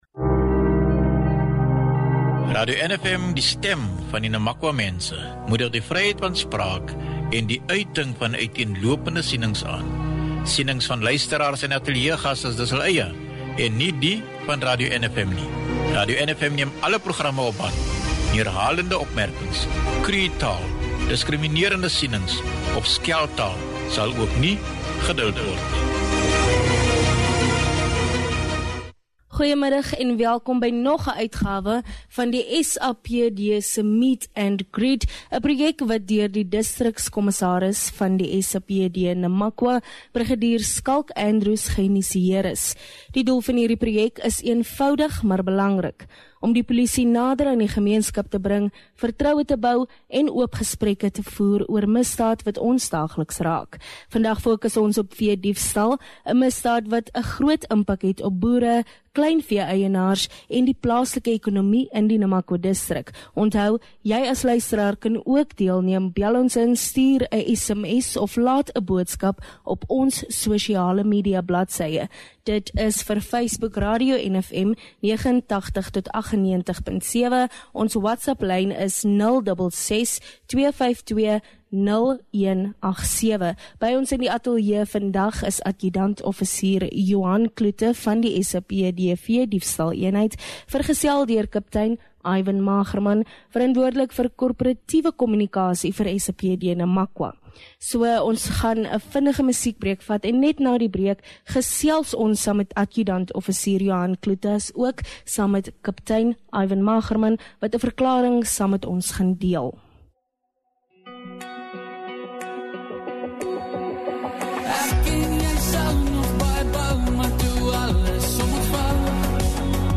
By ons in die ateljee